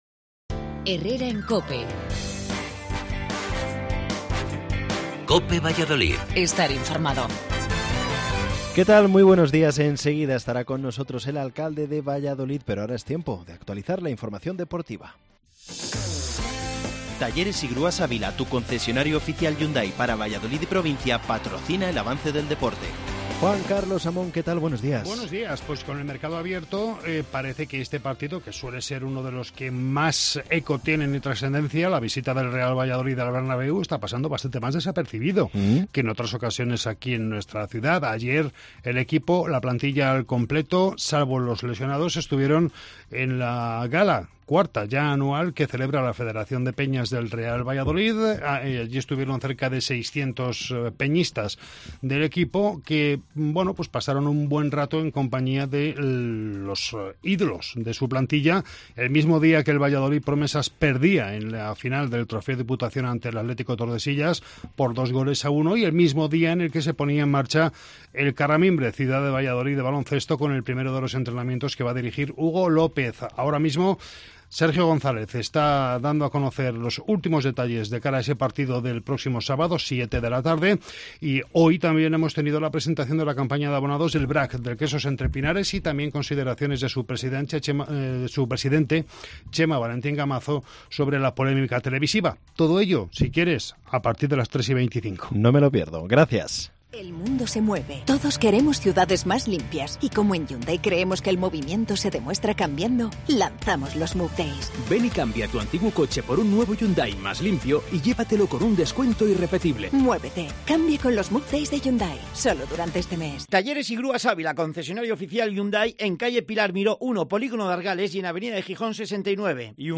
AUDIO: Nos visita el alcalde de Valladolid, Oscar Puente